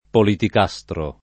politicastro [ politik #S tro ]